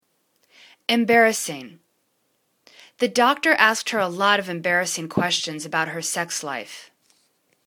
em.bar.ras.sing     /im'barəsing/    adj